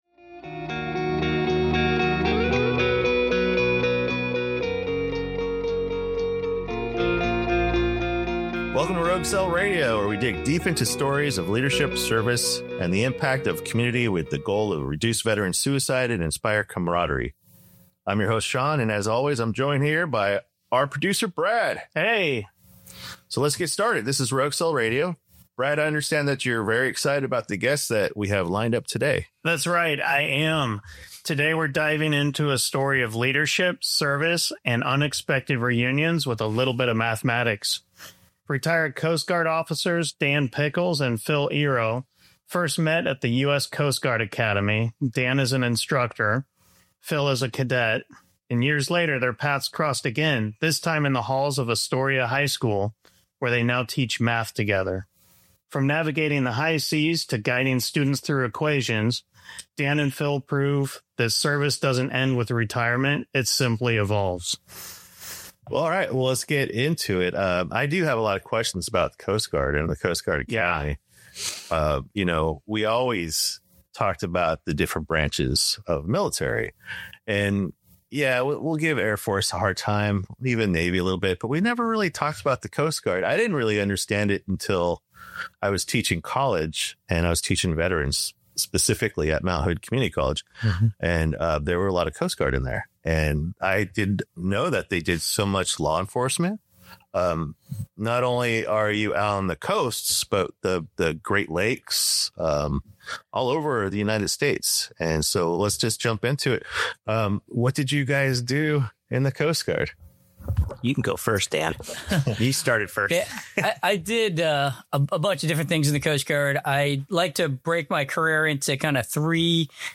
This month Rogue Cell talks with two Coast Guard veterans turned math teachers at Astoria High School. We discuss their service, the Coast Guard Eagle, finding meaning and healing in community, and just a little bit of mathematics.